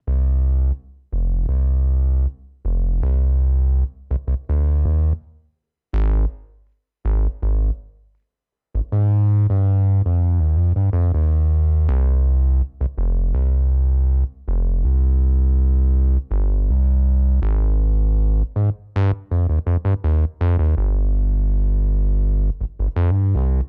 11 bass A.wav